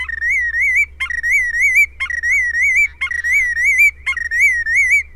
Willet
Willets have an undulating call which sounds like they’re saying “pill-will-willet,” and the bird’s name derives from this distinctive call.